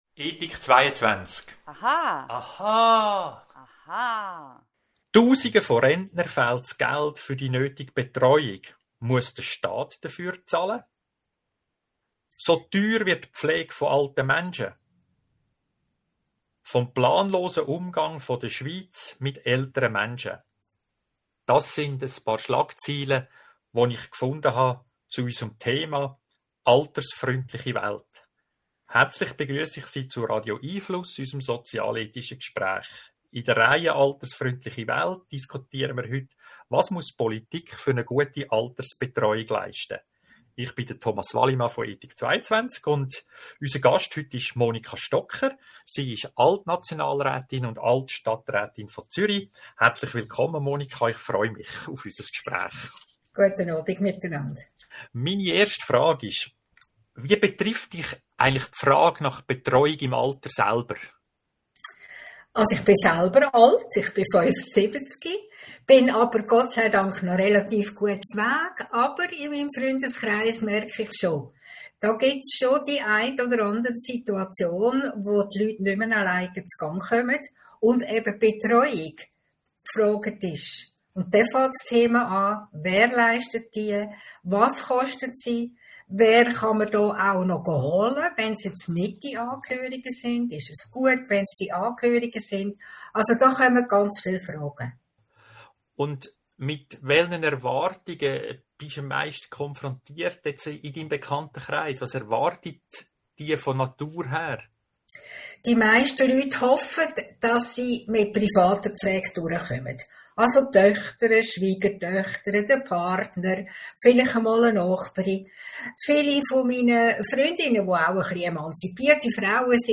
Weitere interessanten Gedanken zum Thema hören Sie im Podcast unseres Gesprächs vom 27. September 2023 mit dem Monika Stocker, Alt-Nationalrätin & Stadträtin, Zürich.